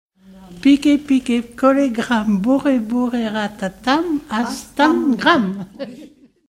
enfantine : comptine
Genre brève
Pièce musicale éditée